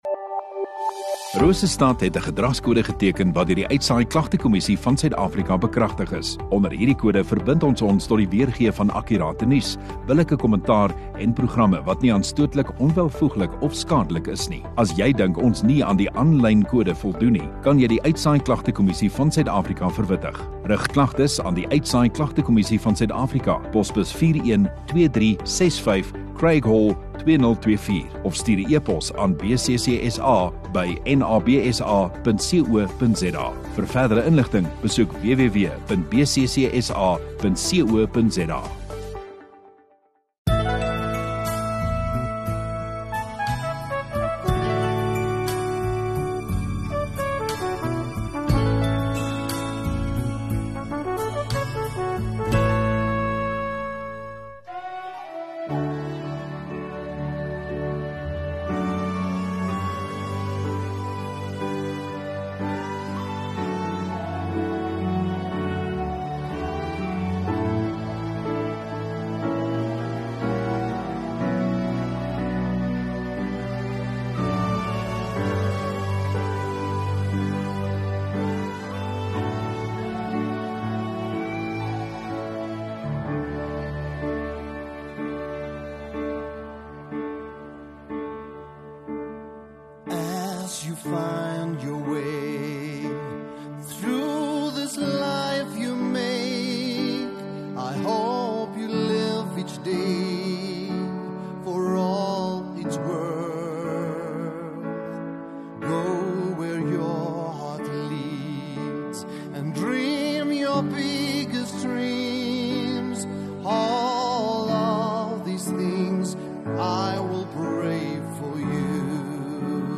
17 Aug Sondagaand Erediens